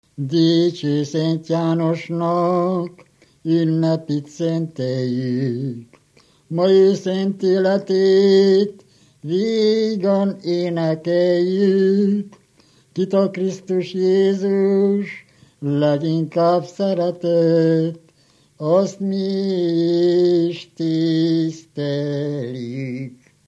Moldva és Bukovina - Bukovina - Istensegíts
Stílus: 9. Emelkedő nagyambitusú dallamok
Kadencia: 1 (5) 3 1